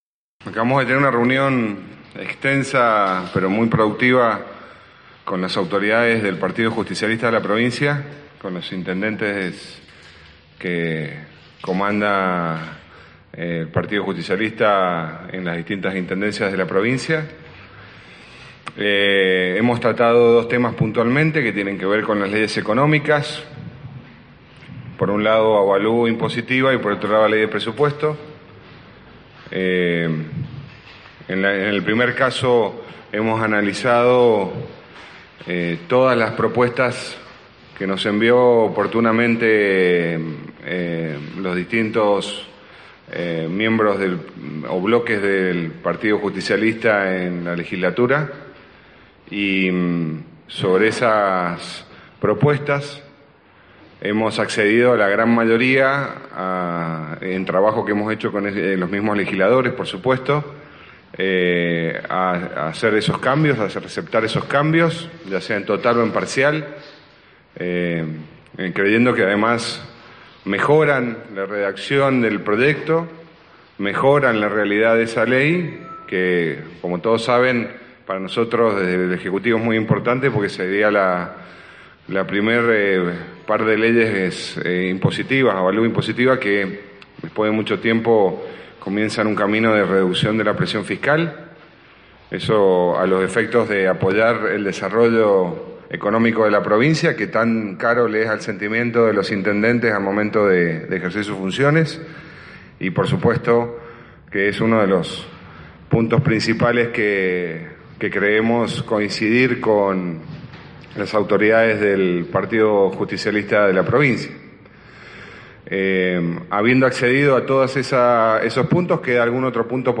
Luego de la reunión, se brindó una conferencia de prensa en la que el ministro de Hacienda y Finanzas de Mendoza, Martín Kerchner; junto a Omar Félix, y Roberto Righi, presidente y vice del Partido Justicialista respectivamente, brindaron detalles sobre los temas tratados.